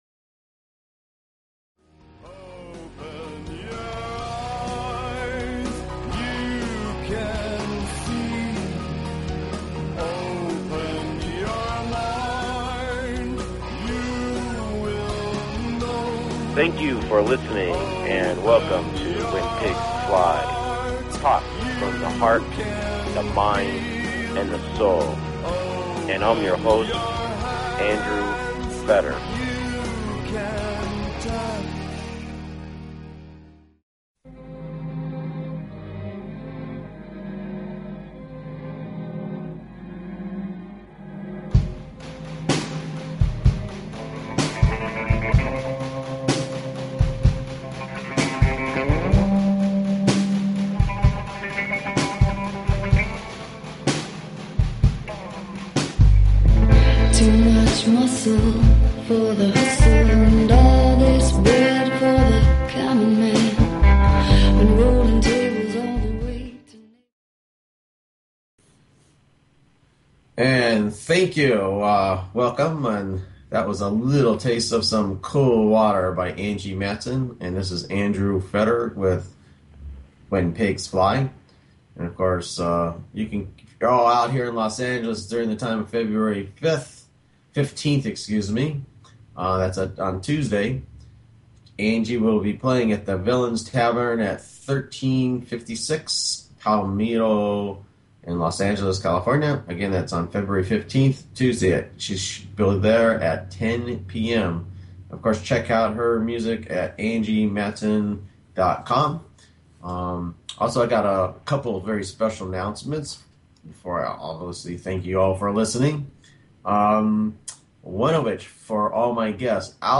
Talk Show Episode, Audio Podcast, When_Pigs_Fly and Courtesy of BBS Radio on , show guests , about , categorized as